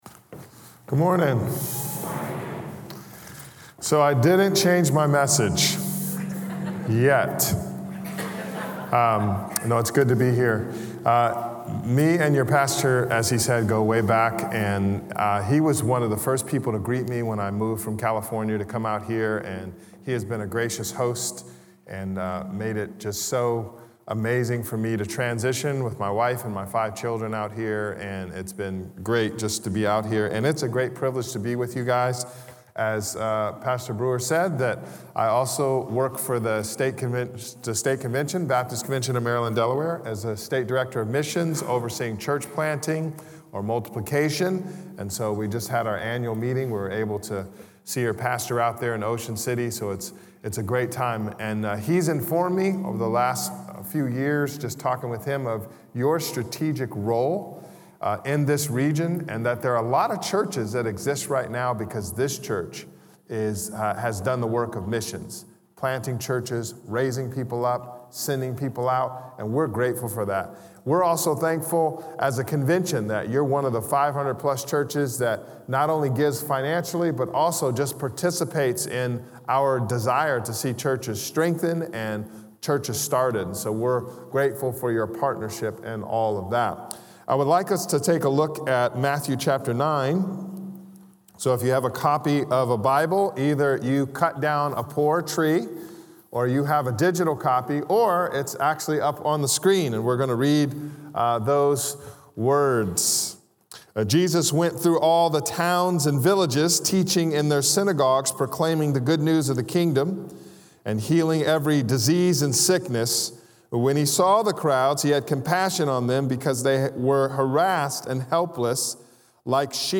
A message from the series "We are the Church."
Messages from guest speakers at our weekend services.